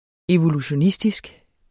Udtale [ evoluɕoˈnisdisg ]